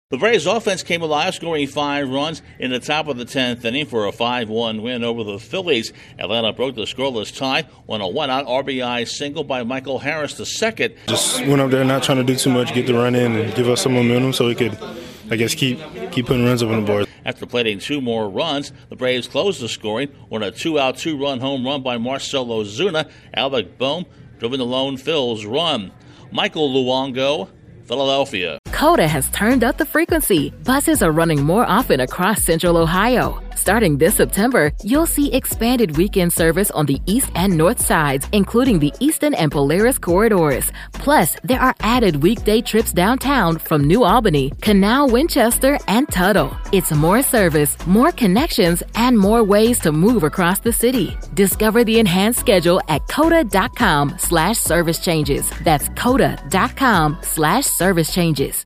The Braves add to their winning streak. Correspondent